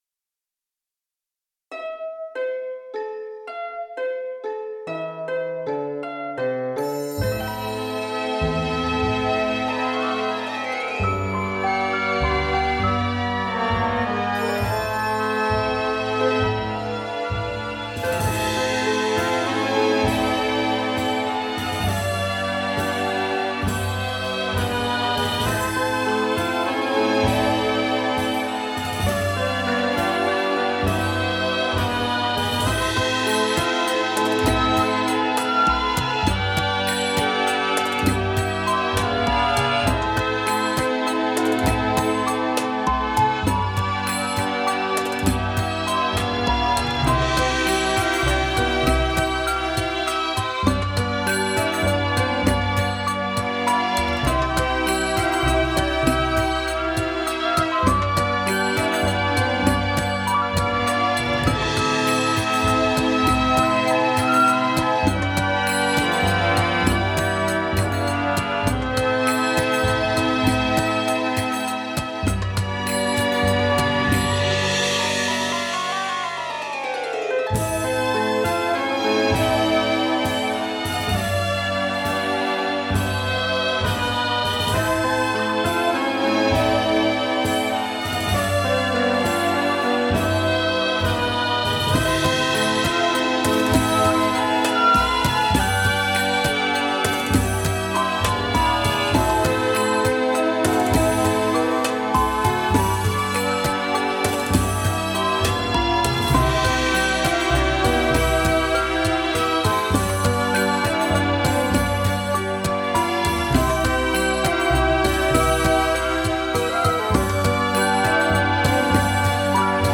Genre: filmscore, classical.